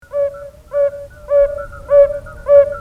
В дневное время самцы желтобрюхих жерлянок поют свои песни, причем не только на поверхности, но и под водой.
Они издают звуки, напоминающие «хуу, хуу».